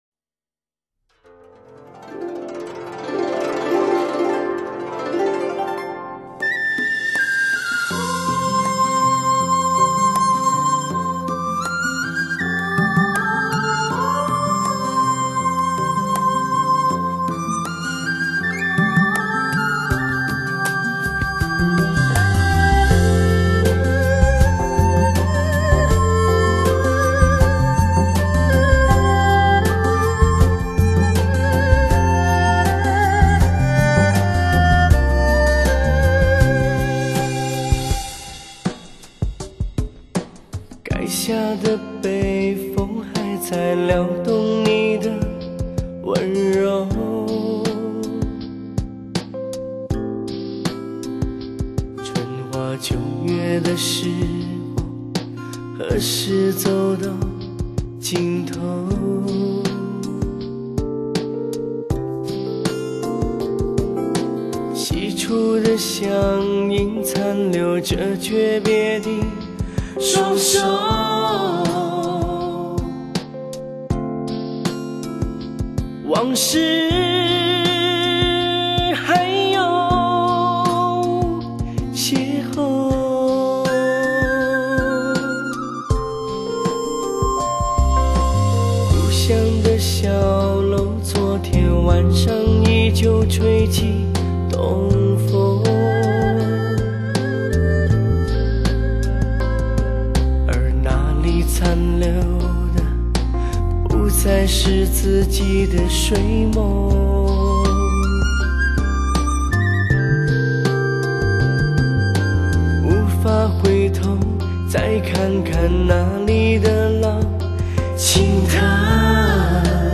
古筝款款，笛子缠绵：那一刻，冰剑饮血，江边那风中的歌声回荡，成为绝唱